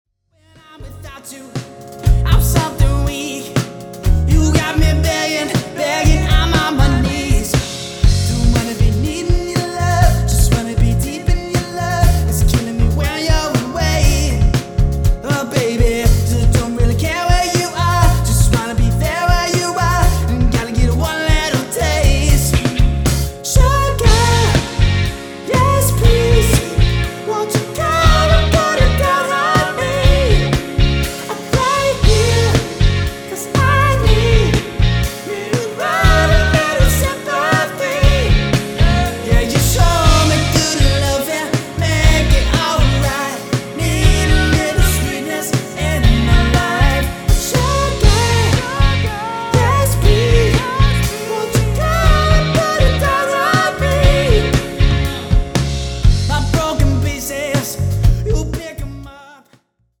Guitar
Bass
Drums